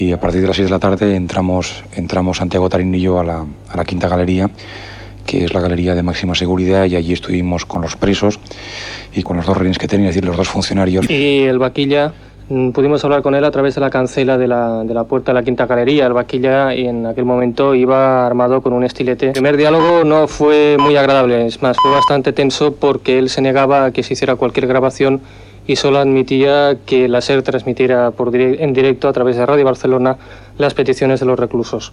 Explicació dels dos periodistes de Ràdio Barcelona que van poder entrar a la presó per informar del motí.
Informatiu